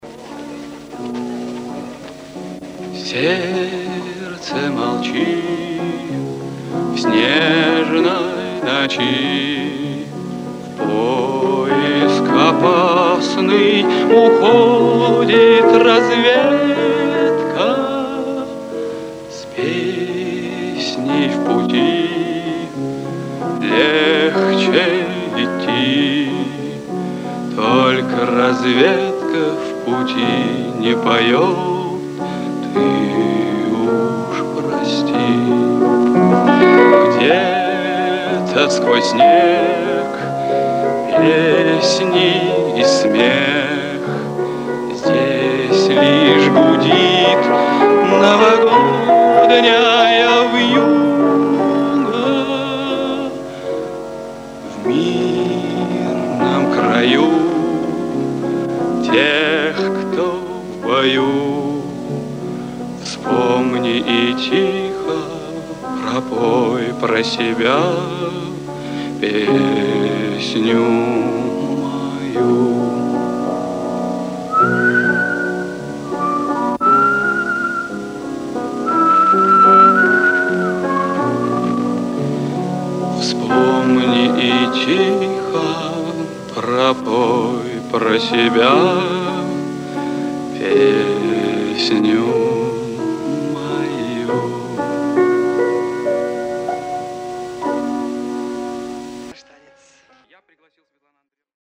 Режим: Mono